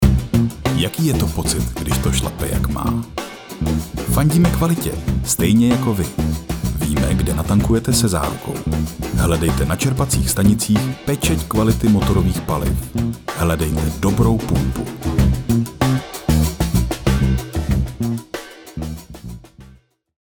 Mužský voiceover - hlas do krátkých reklamních spotů!
Součástí každého jobu je i základní postprodukce, tedy odstranění nádechů, filtrování nežádoucích frekvencí a ekvalizace a nastavení exportu minimálně 96kHz/24bit, okolo -6dB, jestli se nedohodneme jinak.